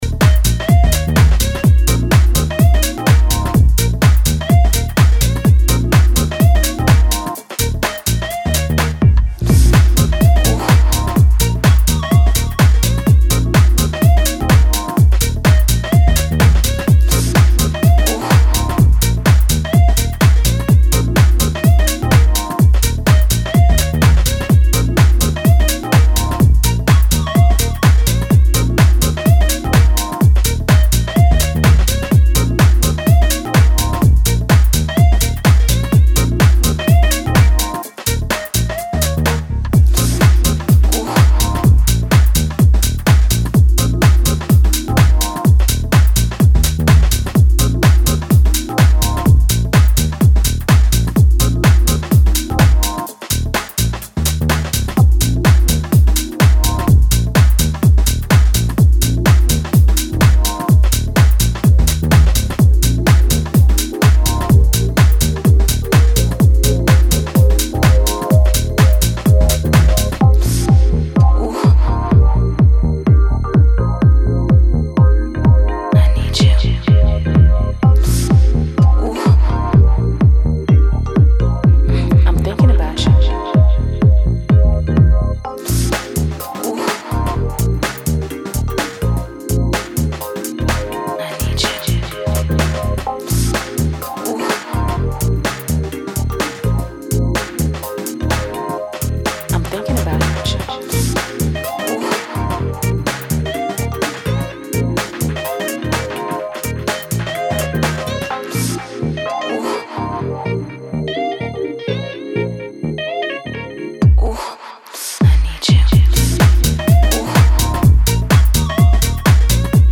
powerful vibes and funky grooves.
house music